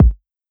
KICK (STARGAZING).wav